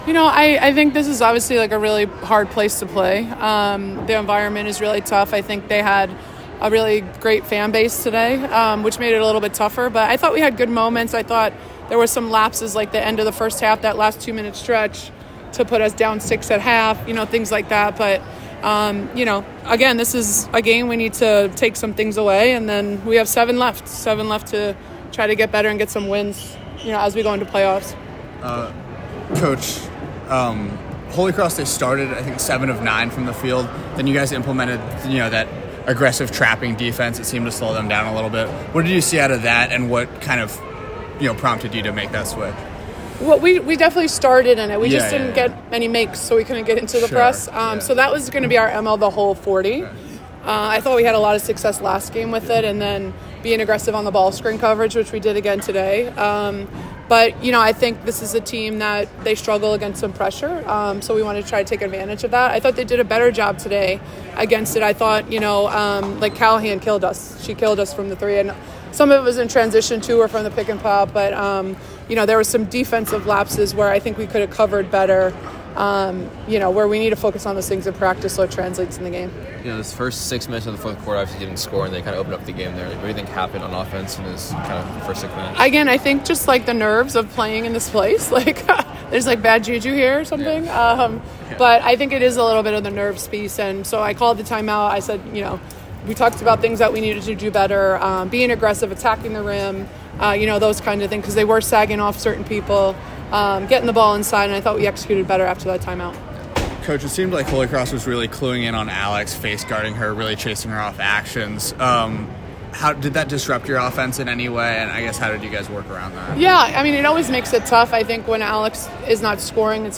WBB_HC_Postgame.mp3